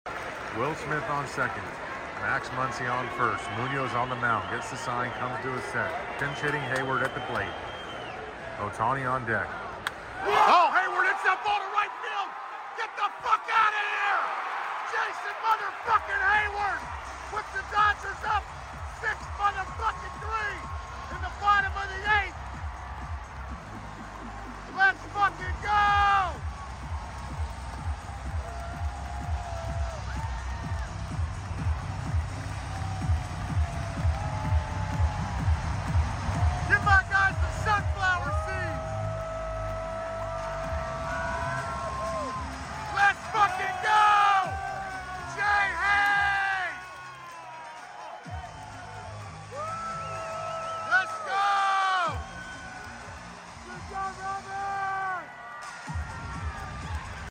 ENJOY THE PLAY BY PLAY Sound Effects Free Download